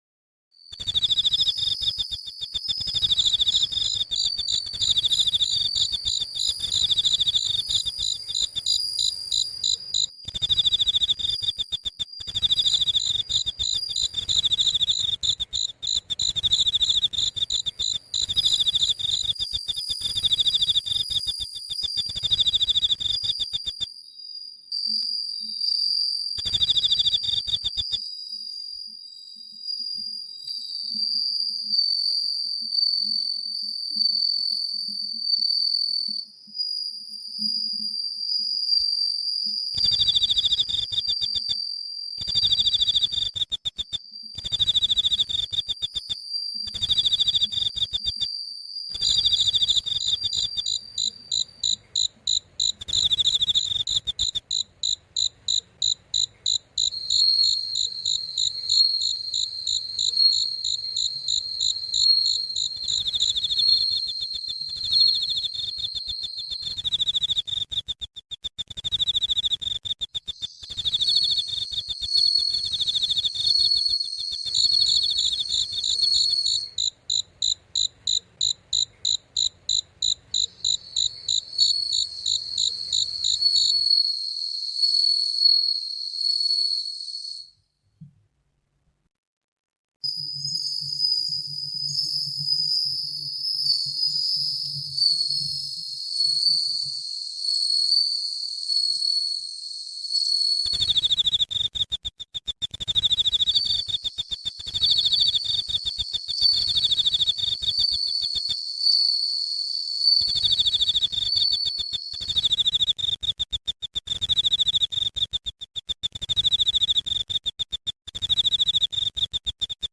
Vollmondnacht Grillen & Grillen-Sounds | Alpha-Wellen für Schlaf & Heilung